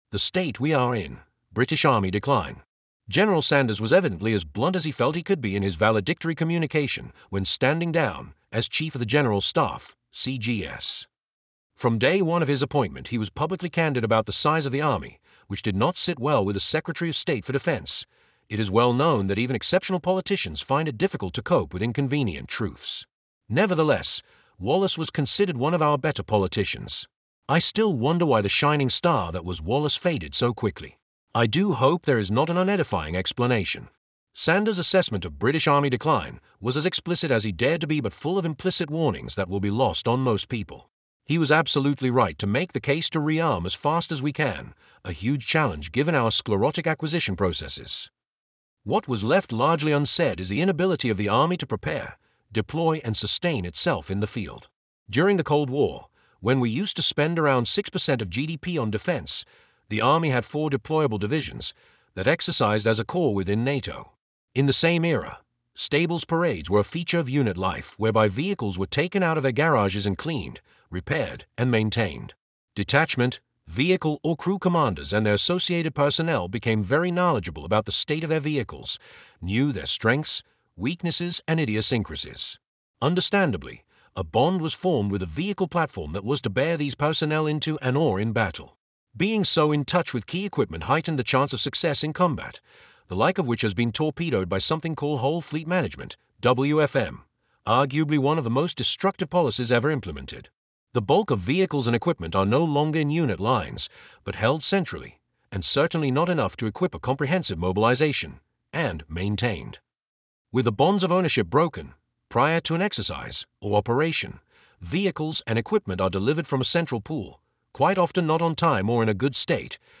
An improved audio format version of our written content. Get your defence and security perspectives now through this podcast.